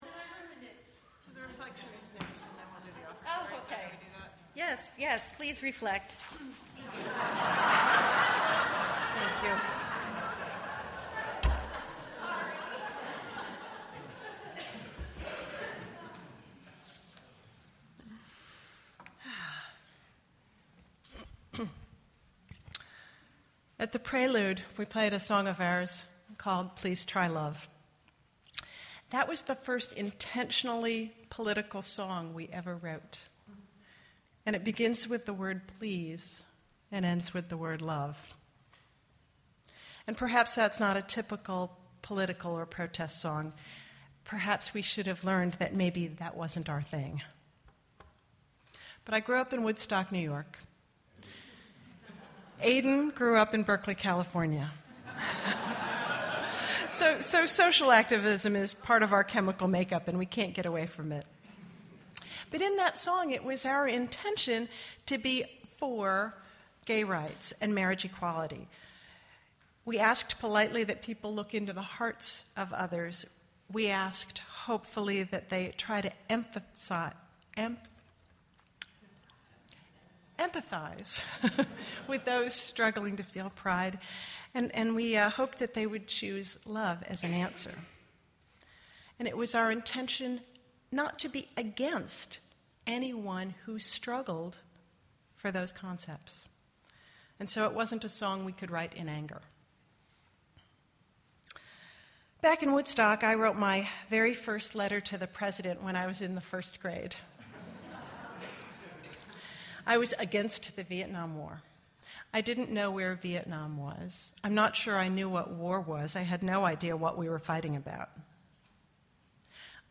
Modern-folk duo Friction Farm is a husband-and-wife team of traveling troubadours.